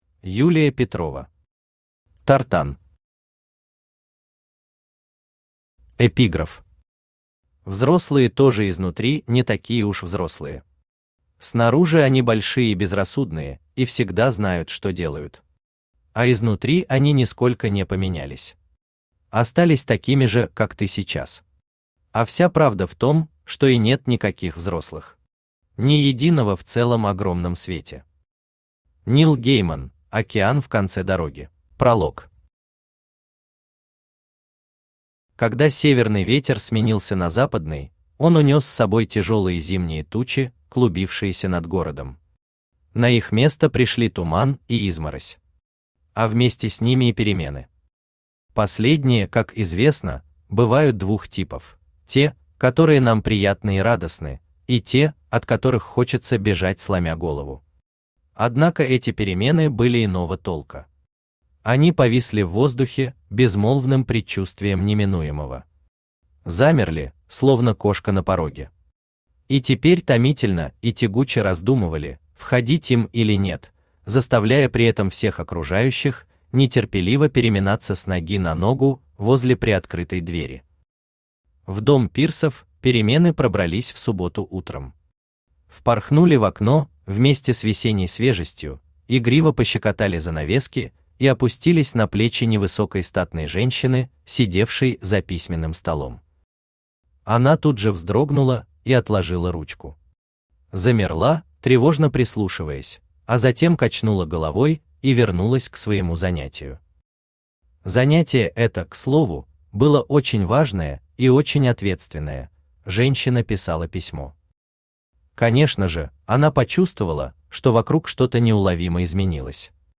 Аудиокнига Тартан | Библиотека аудиокниг
Aудиокнига Тартан Автор Юлия Александровна Петрова Читает аудиокнигу Авточтец ЛитРес.